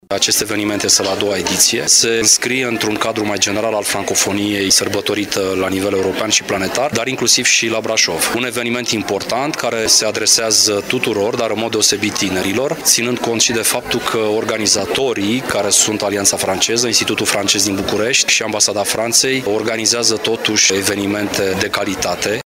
Ca în fiecare an, Luna Francofoniei este sărbătorită și la Brașov cu diverse evenimente organizate de Alianța Franceză, spune consulul onorific al Franței la Brașov, Ion Ciolacu.